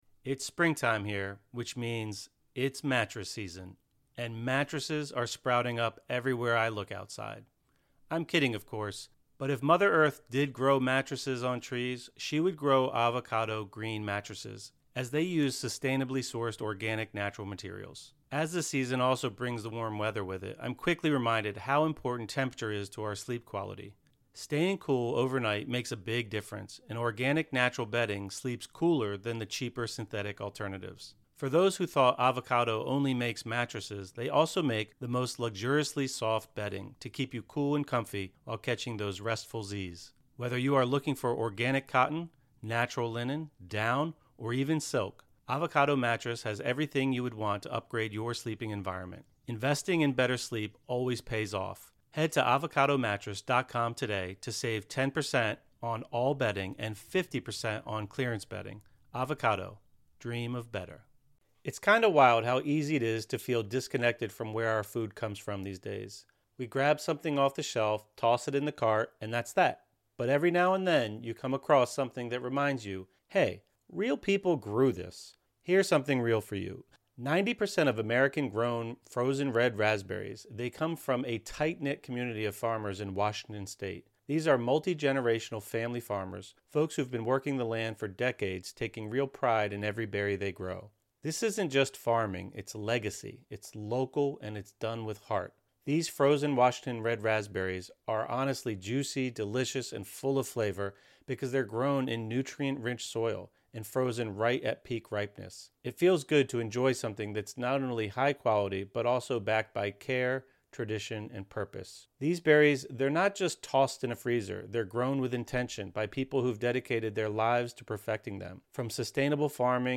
Sleep Meditation | Open Your Heart and Heal Your Energy (; 17 May 2025) | Padverb